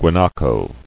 [gua·na·co]